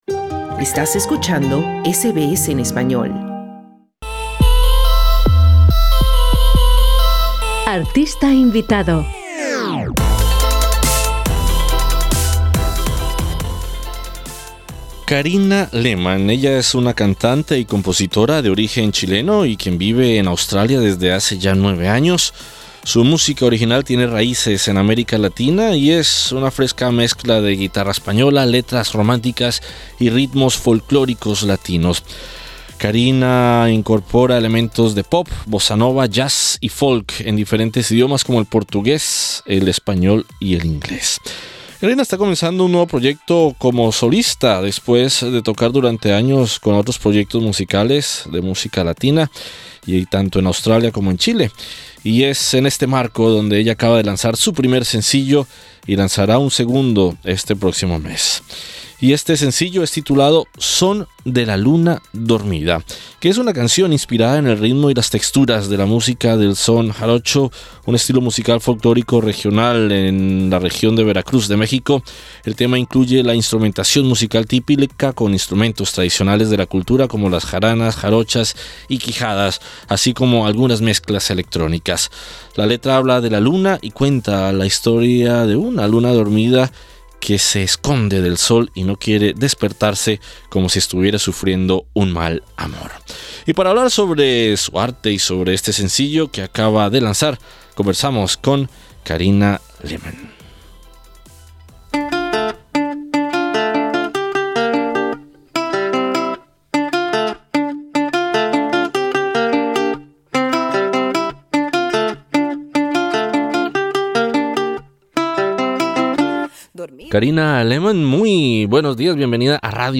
Para hablar cobre su arte y sobre este sencillo que acaba de lanzar, conversamos con